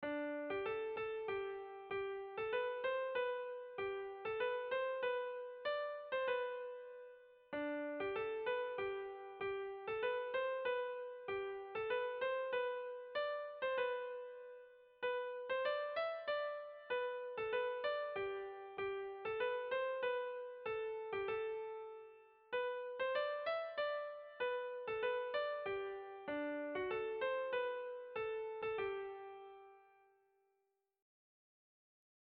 Kontakizunezkoa
Zortziko handia (hg) / Lau puntuko handia (ip)
AAB1B2